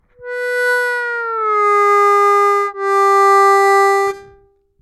-3 -3'''+3 плавно